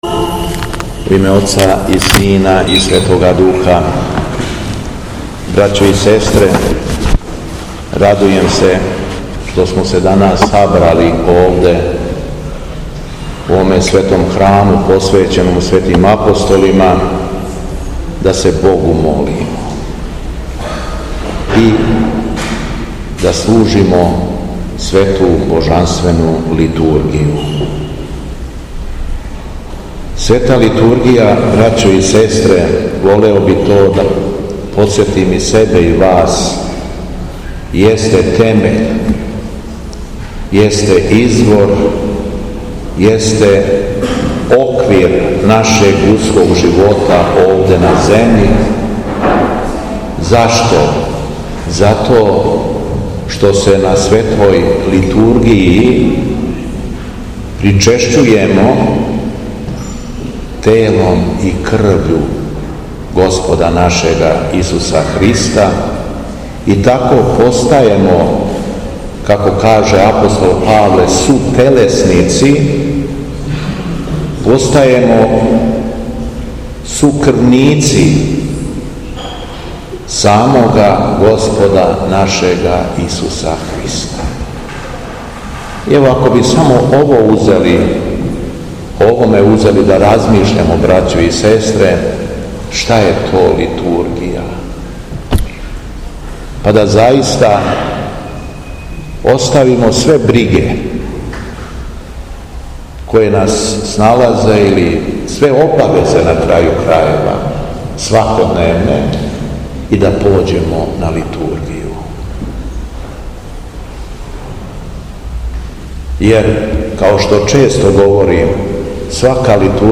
АРХИЈЕРЕЈСКА ЛИТУРГИЈА У ЈАГОДИНИ
Беседа Његовог Преосвештенства Епископа шумадијског г. Јована
На дан молитвеног прослављања Светог Аврамија и Анастасије Римљанке, у суботу 29. октобра/11. новембра 2023. године, централну јагодинску цркву Светих апостола Петра и Павла посетио је Његово преосвештенство Епископ шумадијски Господин Јован.